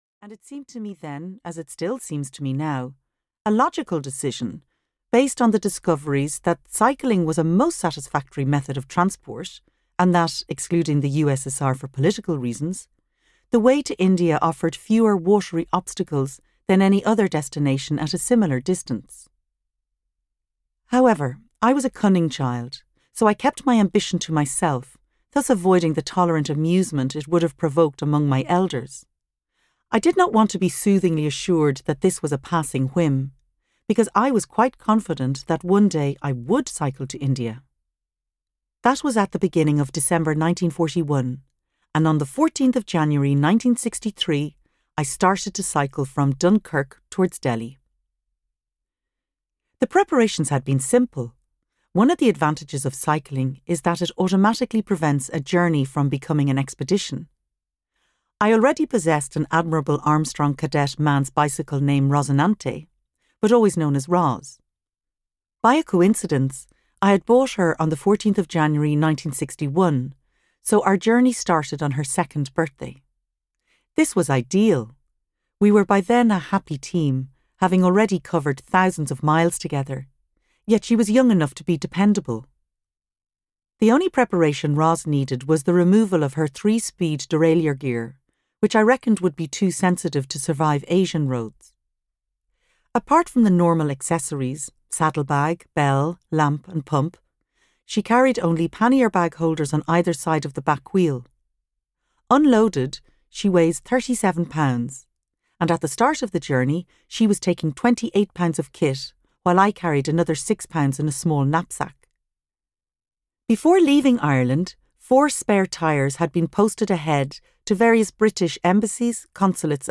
Full Tilt (EN) audiokniha
Ukázka z knihy